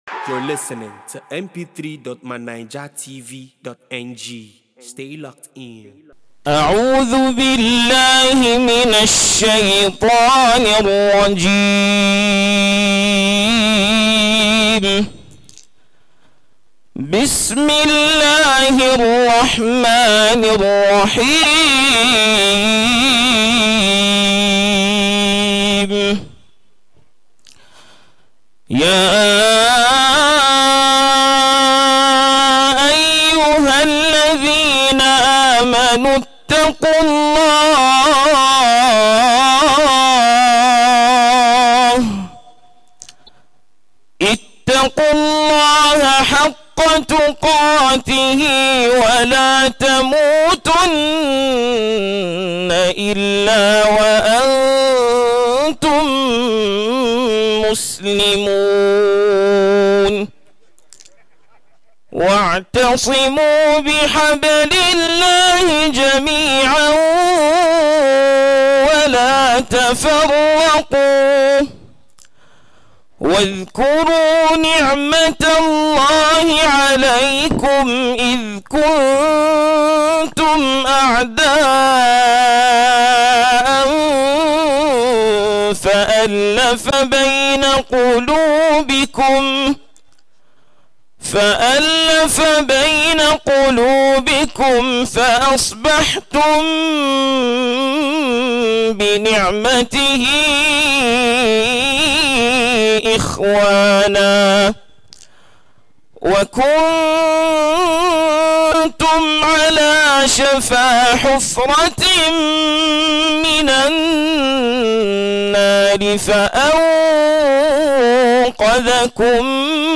BEAUTIFUL QURAN RECITATION